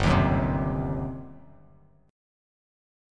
GM7sus4#5 chord